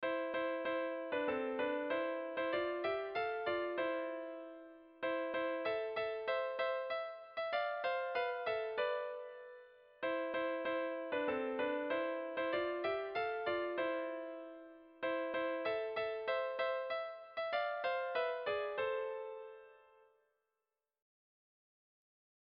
Sehaskakoa
ABAB